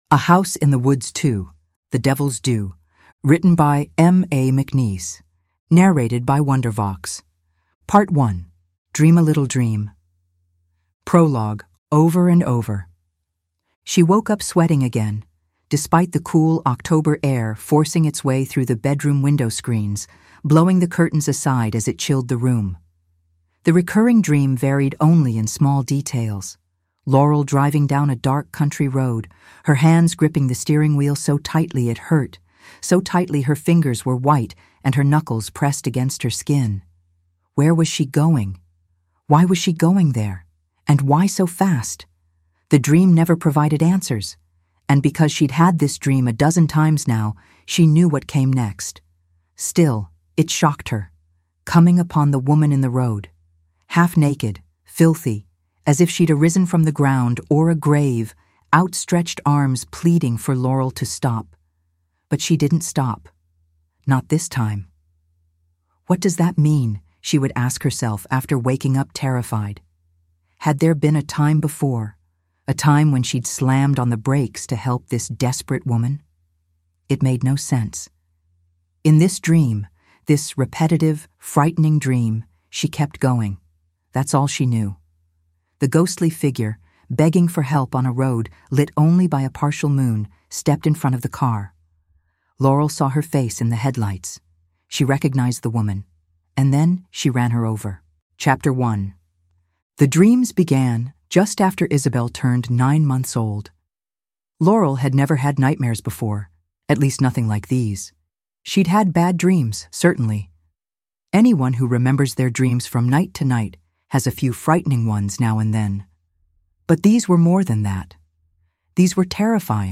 Welcome to the episodic audio edition of A House in the Woods 2: The Devil’s Due.
This is not an audiobook in the conventional sense, and no audiobook narrators living or dead were harmed in its production.